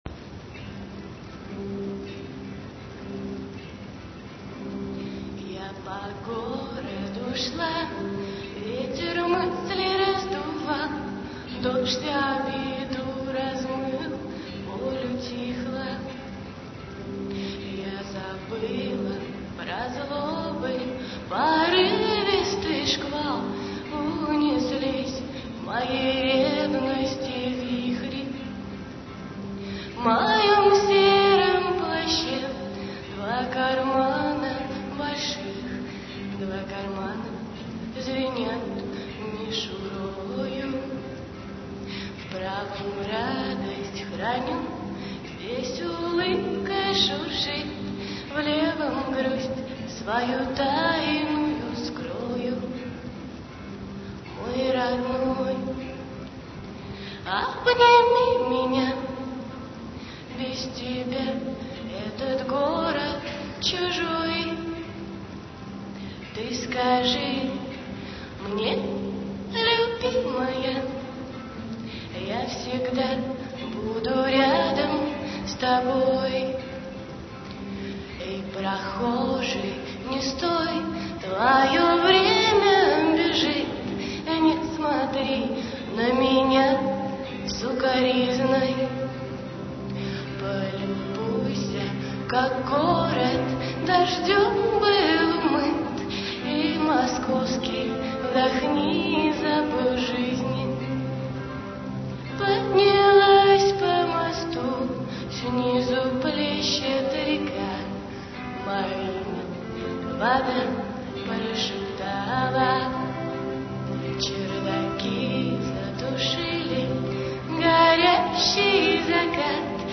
458 kb, авторское исполнение